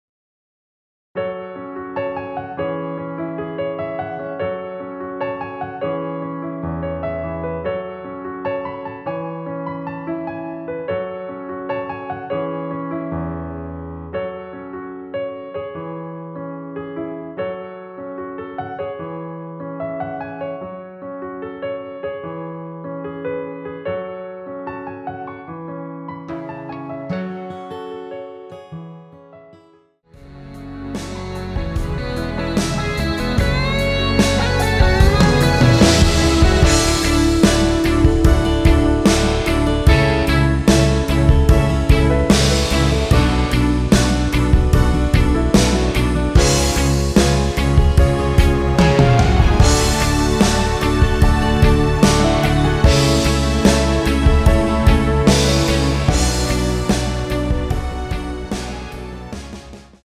원키에서(-5)내린 MR입니다.
F#m
앞부분30초, 뒷부분30초씩 편집해서 올려 드리고 있습니다.